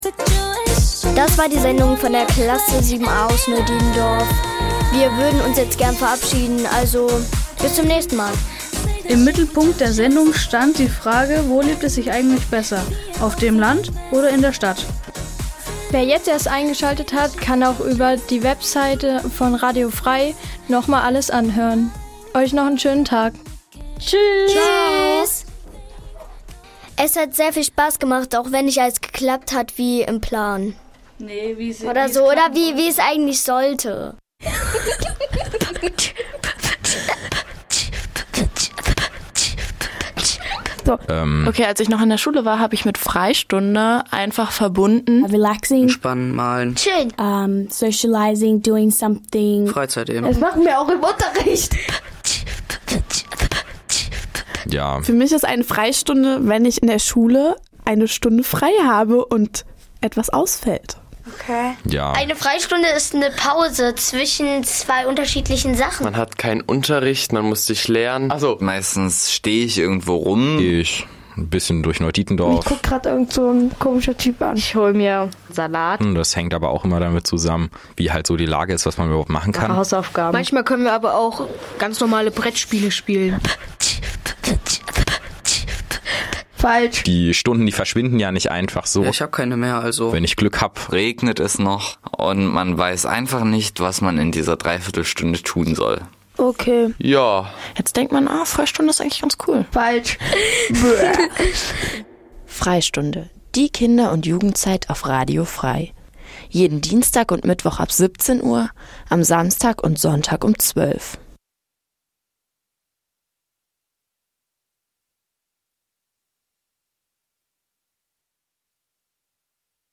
Easy Listening Dein Browser kann kein HTML5-Audio.